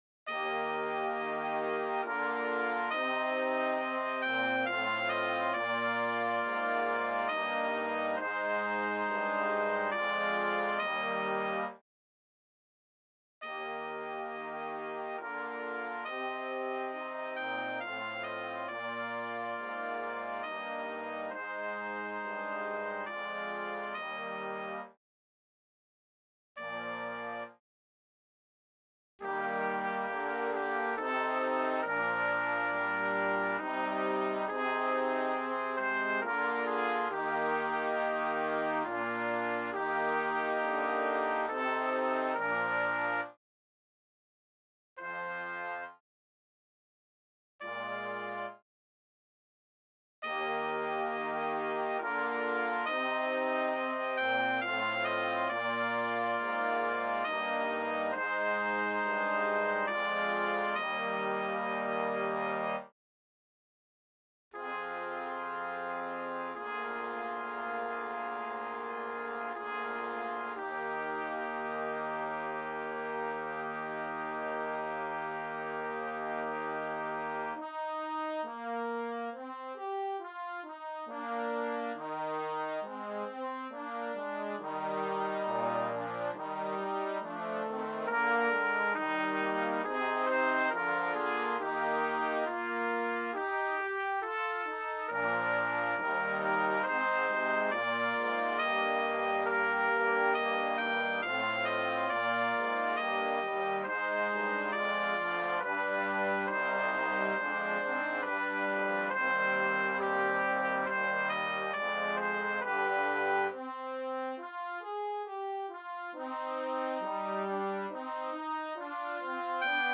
BRASS QUARTET